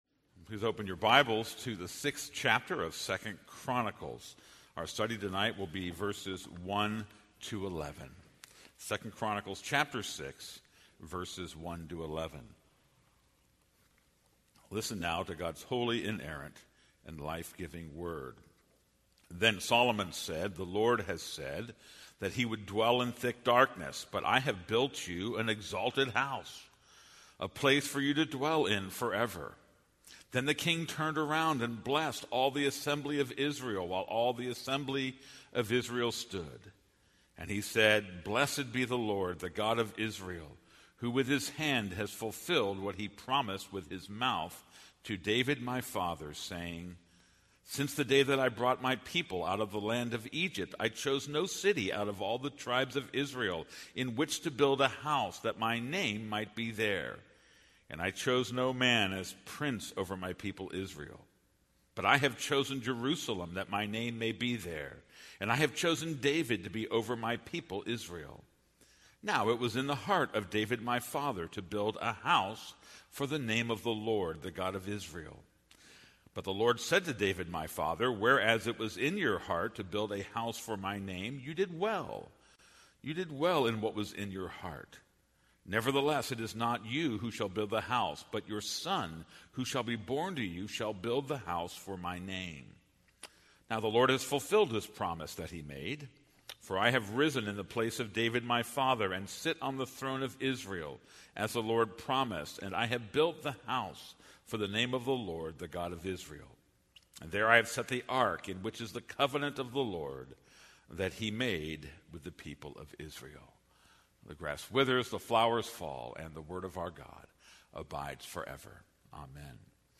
This is a sermon on 2 Chronicles 6:1-11.